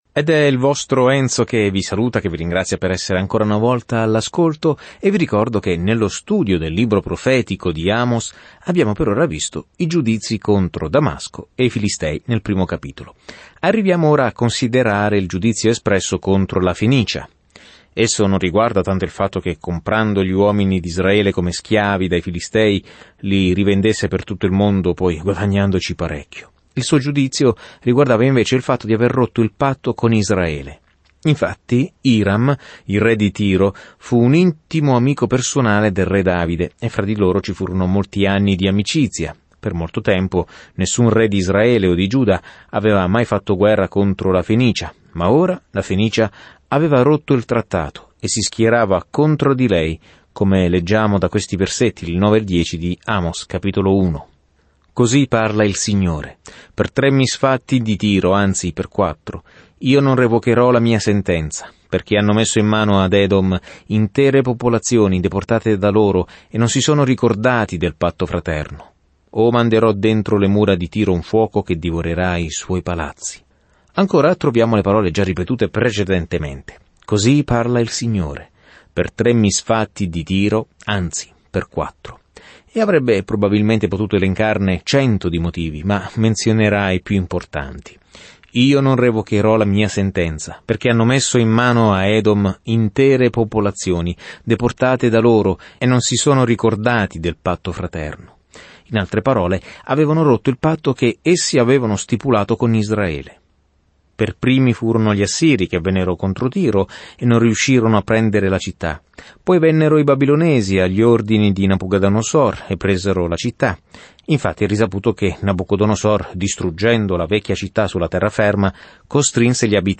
Scrittura Amos 1:9-15 Amos 2:1-3 Giorno 2 Inizia questo Piano Giorno 4 Riguardo questo Piano Amos, un predicatore di campagna, va nella grande città e condanna i loro modi peccaminosi, dicendo che siamo tutti responsabili davanti a Dio secondo la luce che ci ha dato. Viaggia ogni giorno attraverso Amos mentre ascolti lo studio audio e leggi versetti selezionati della parola di Dio.